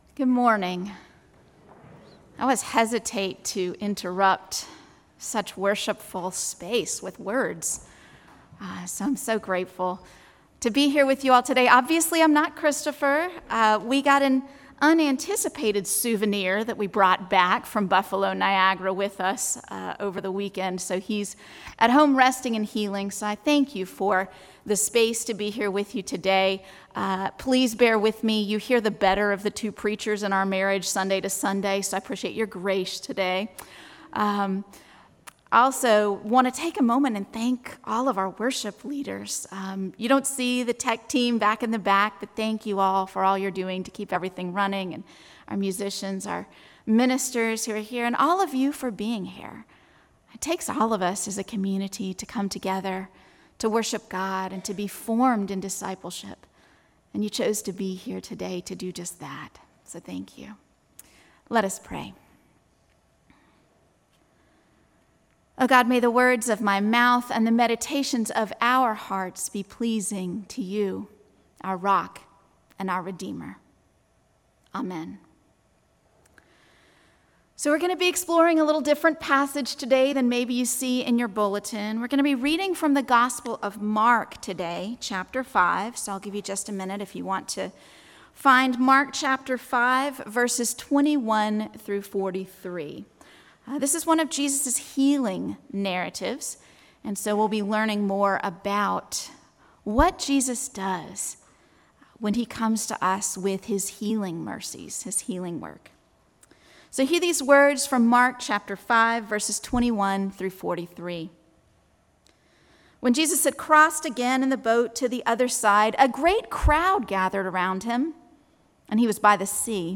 Mark 5:21-43 Service Type: Guest Preacher What might it mean to trust in God’s healing